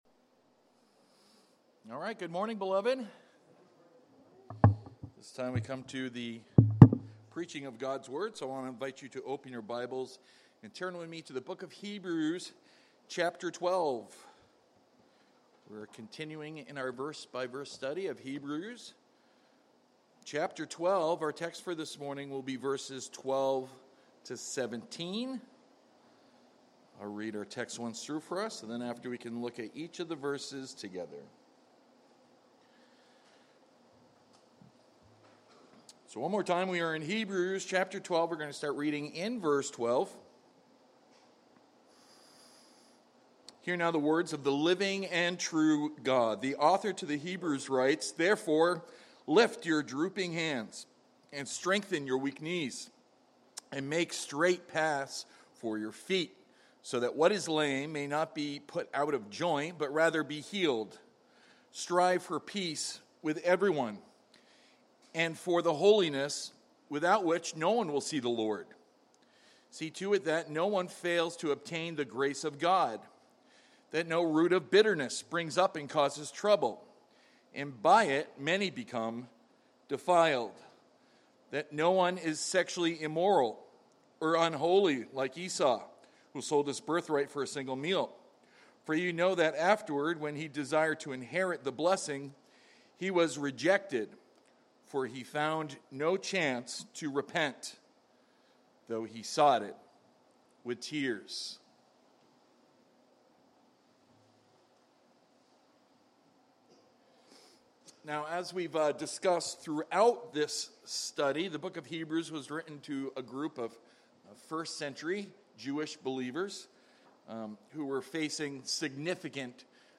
Sermons by At the Cross